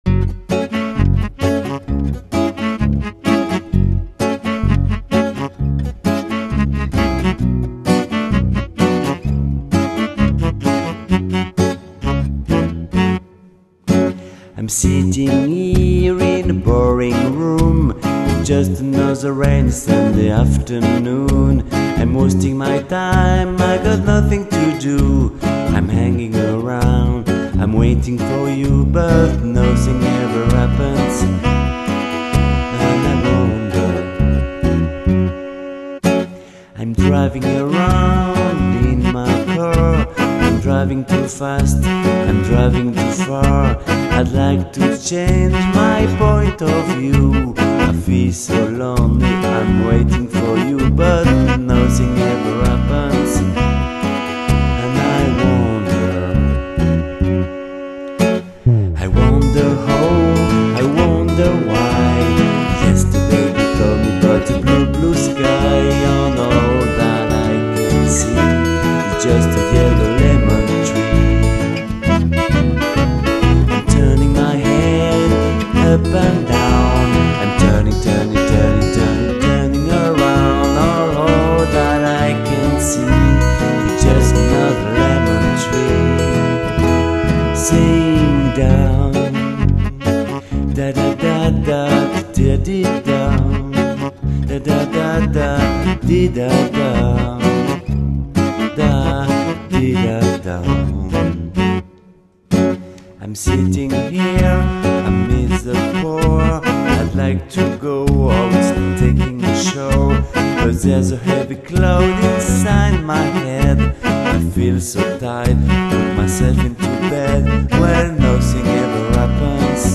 version + sax